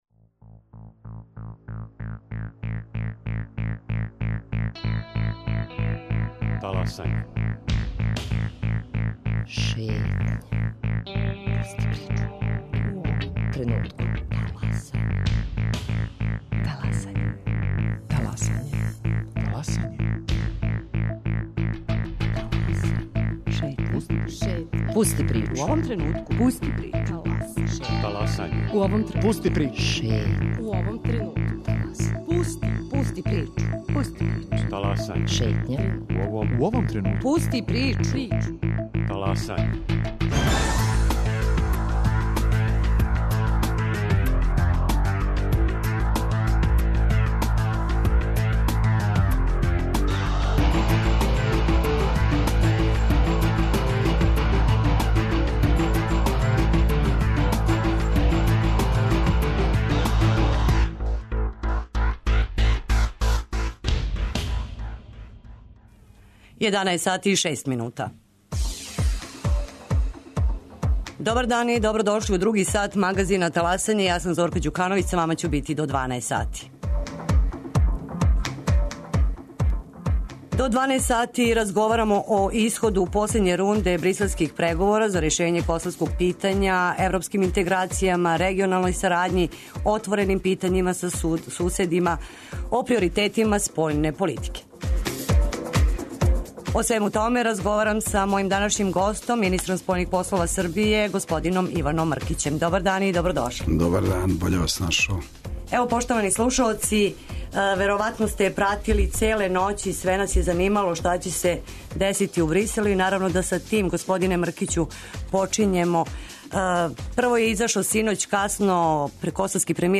О исходу последње рунде бриселских преговора за решење косовског питања, европским интеграцијама, регионалној сарадњи, отвореним питањима са суседима и о приоритетима спољне политике, разговарамо са Иваном Мркићем, министром спољних послова Србије.
intervjui.mrkic.mp3